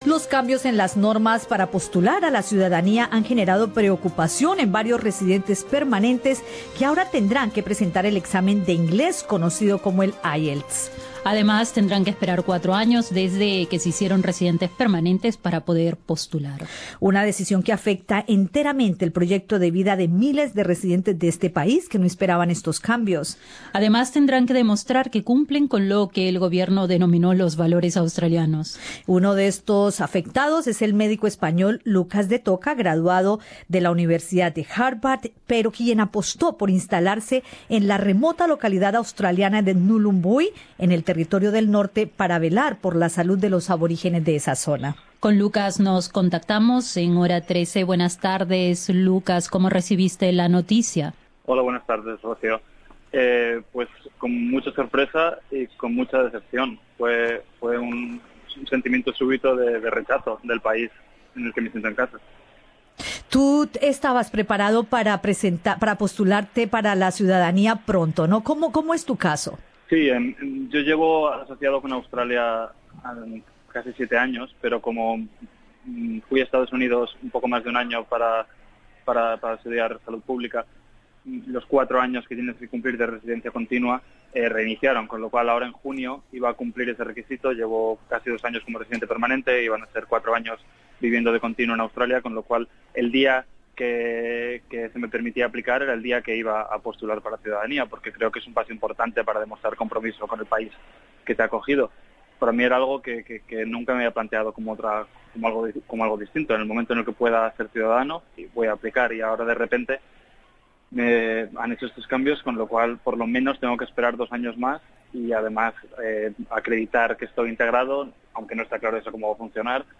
Escucha el podcast con la entrevista con el médico